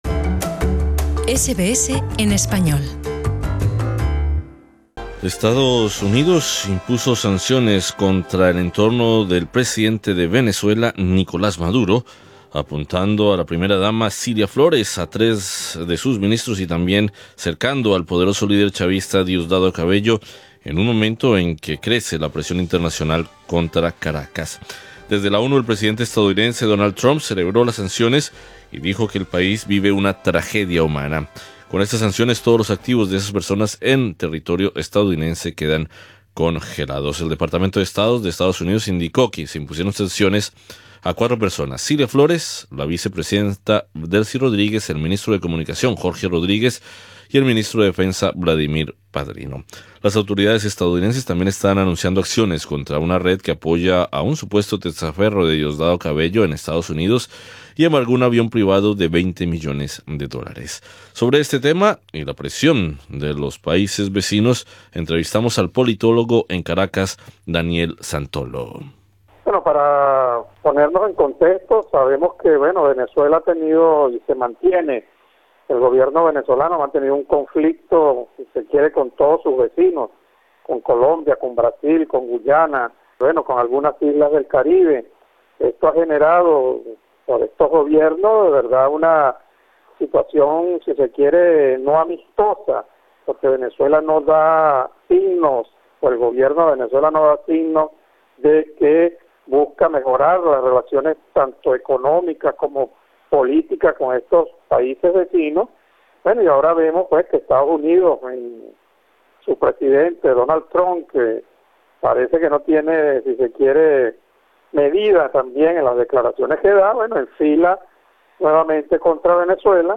Sobre este tema y la presión de los países vecinos, entrevistamos al politólogo en Caracas